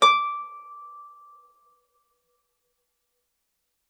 KSHarp_D6_mf.wav